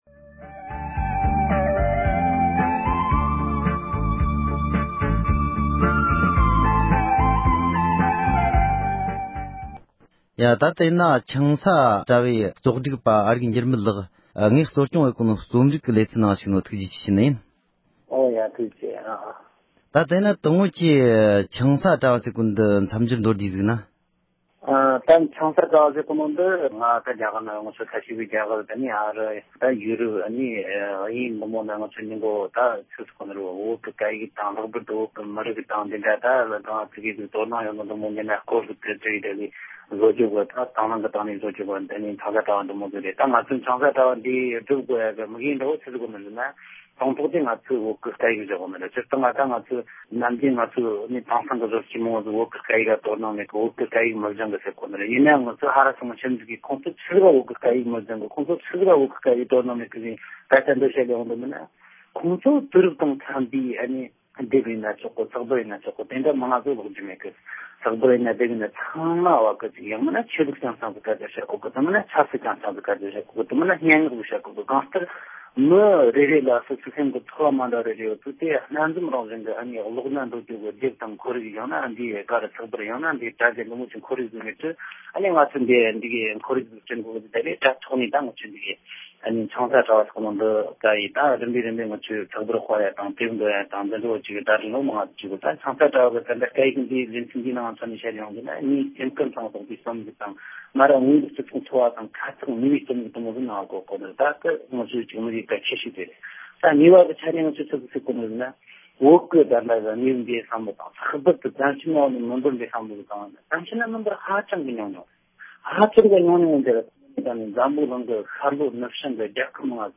བཅར་འདྲི